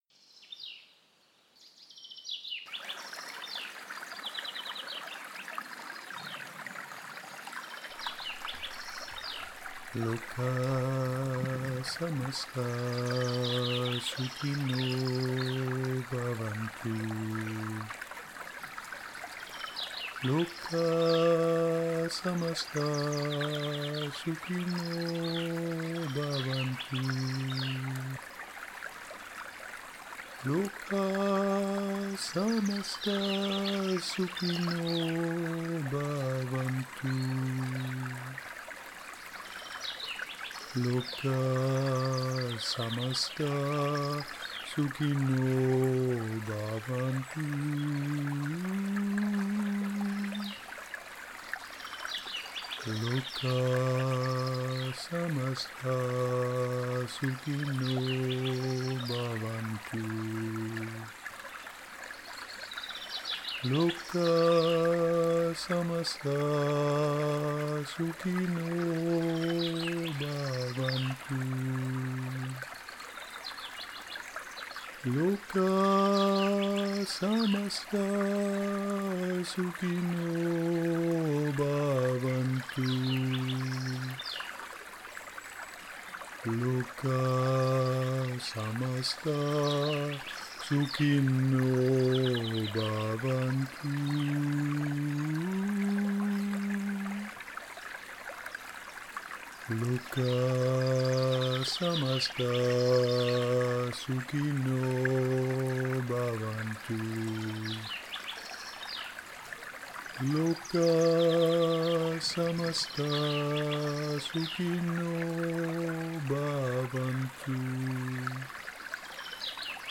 Mantra